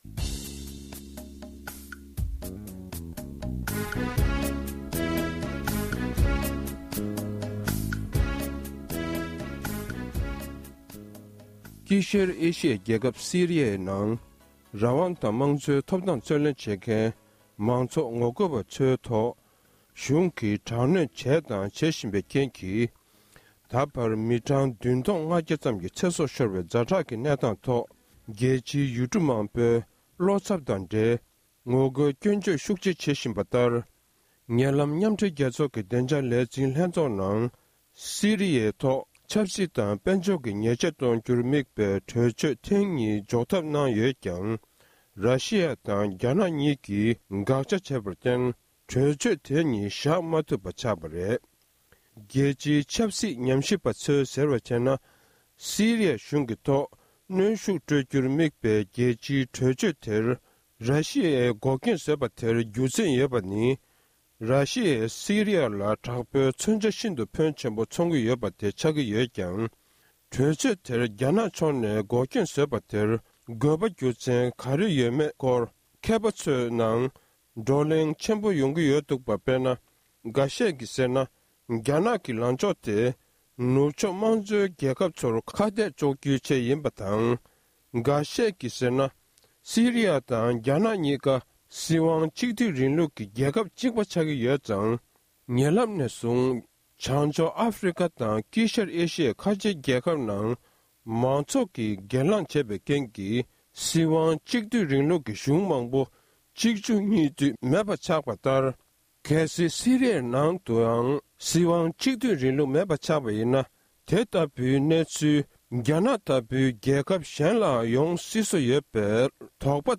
དགོངས་ཚུལ་བཀའ་འདྲི་ཞུས་པར་གསན་རོགས༎